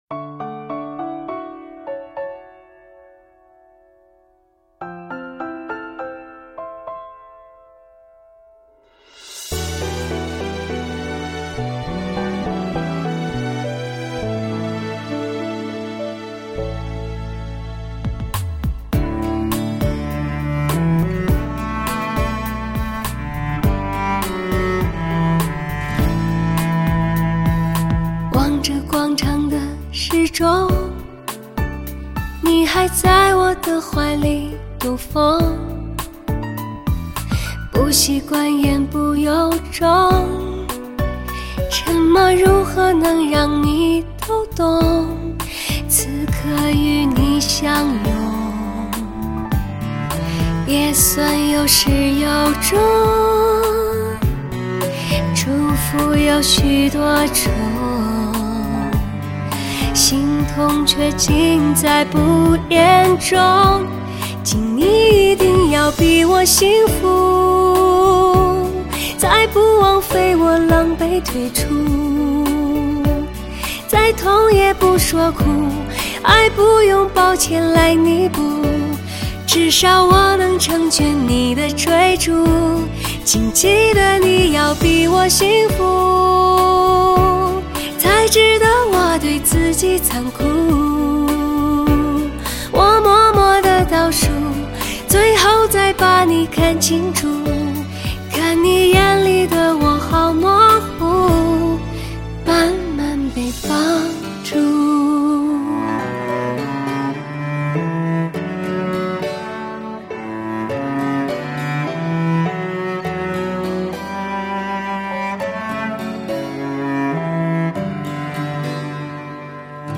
醇厚、沧桑、细腻、浪漫，舟音绕梁，歌声蜕变，极度诱人，HD直刻无损高音质音源技术，HIFI限量珍藏版。
她的歌声带给人们的将是唯美的伸张，听觉神经上的抒缓与心灵里快乐的流淌……